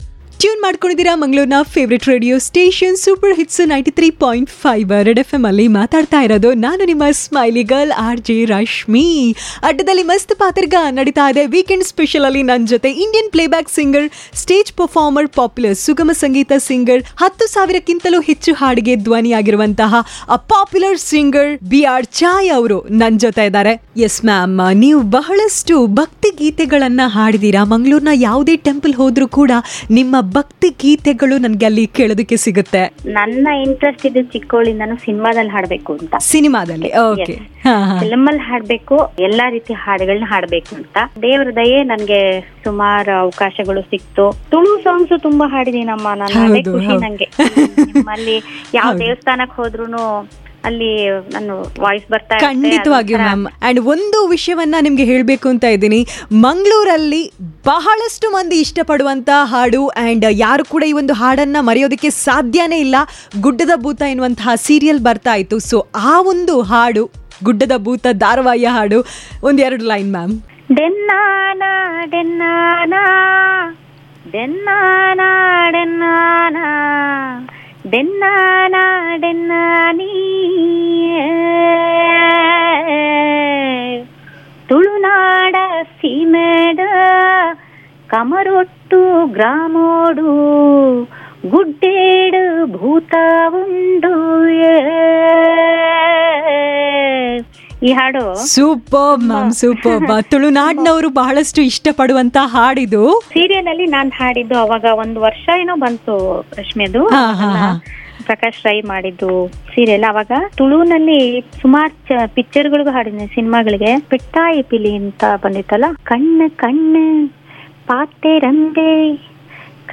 Interview with B R Chaya - 02